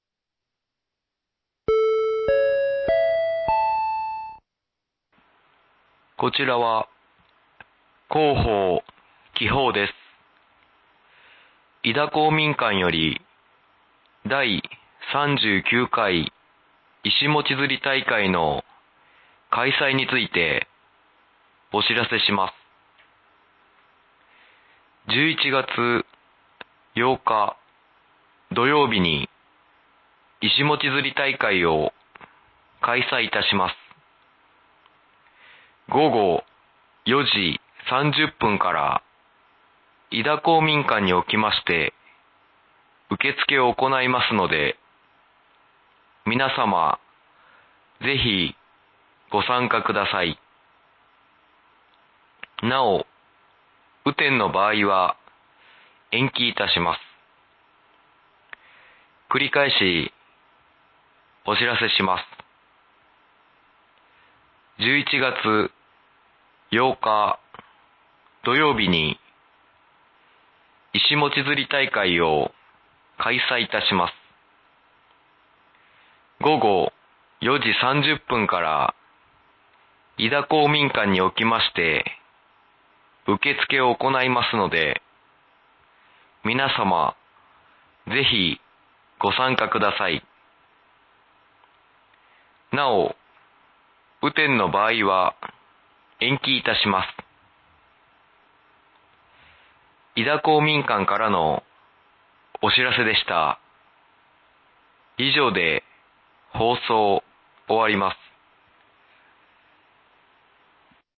※井田地区のみ放送 １１月８日（土） いしもち釣り大会を開催いたします。